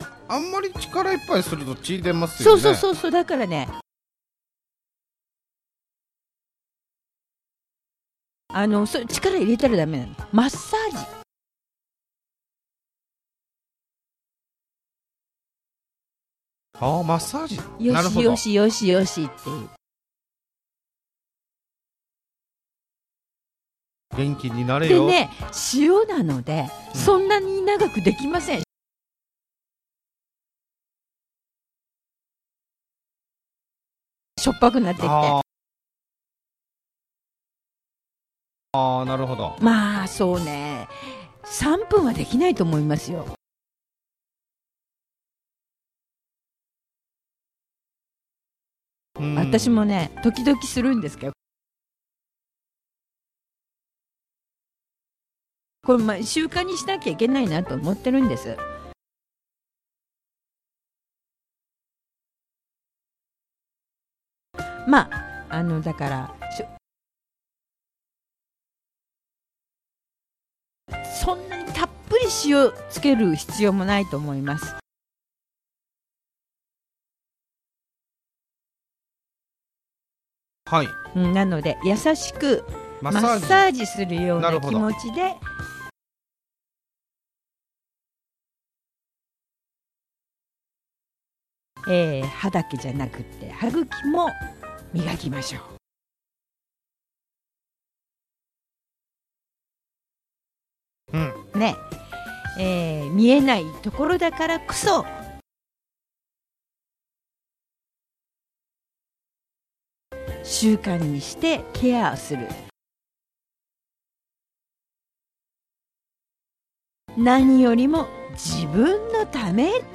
Slow Speed
Slow Speed with Pauses